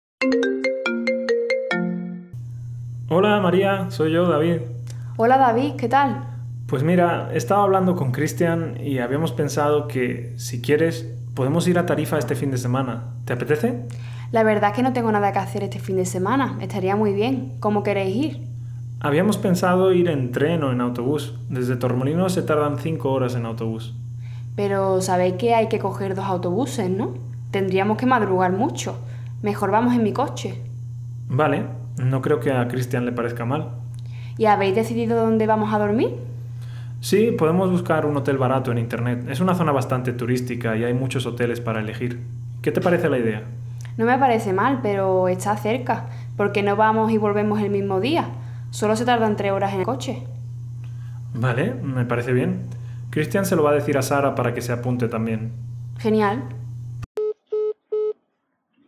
Proponer-planes-tarifa-diálogo-B1-7.wav